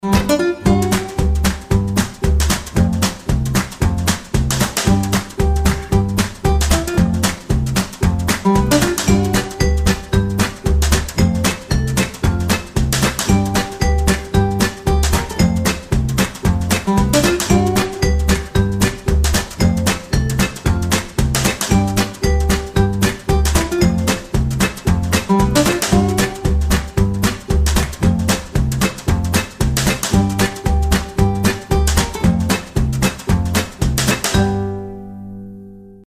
• Качество: 320, Stereo
гитара
мелодичные
веселые
без слов
инструментальные
озорные
джаз
акустическая гитара